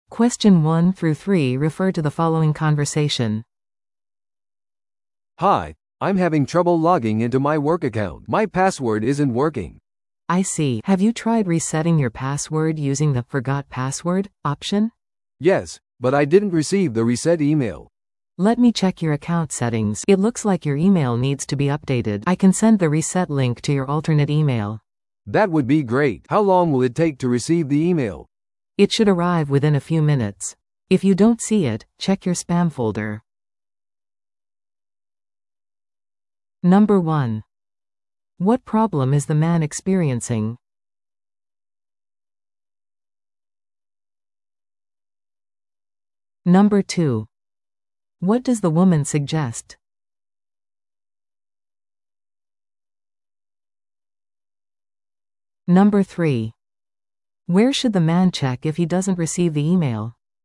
TOEICⓇ対策 Part 3｜ログイン問題に関するITサポート会話 – 音声付き No.59
No.1. What problem is the man experiencing?
No.2. What does the woman suggest?